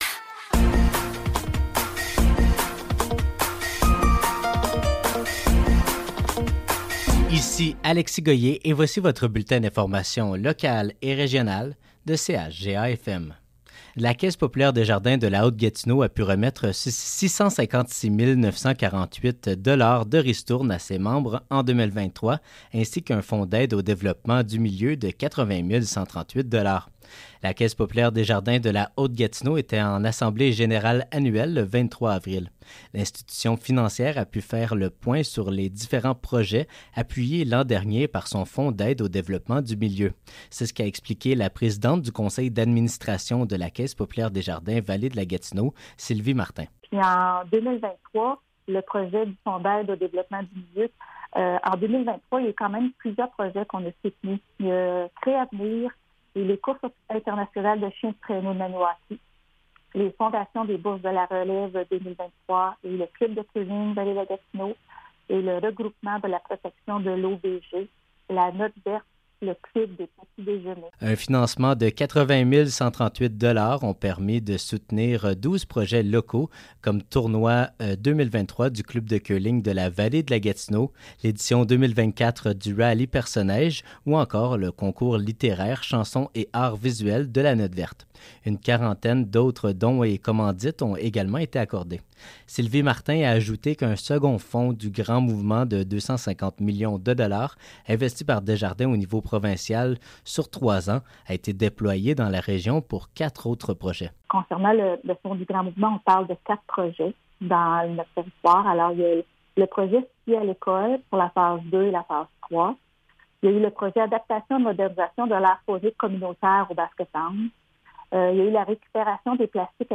Nouvelles locales - 26 avril 2024 - 12 h